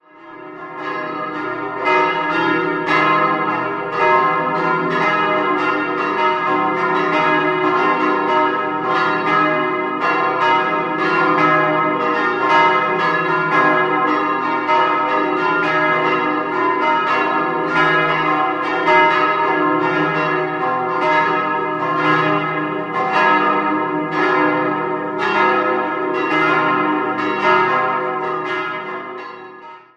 Das Gotteshaus wurde Ende des 17. Jahrhunderts im Barockstil errichtet, erhielt ihr heutiges Aussehen jedoch erst um 1820. Idealquartett: c'-es'-f'-as' Die Glocken wurden (höchstwahrscheinlich) 1946 von Johann Hahn in Landshut gegossen.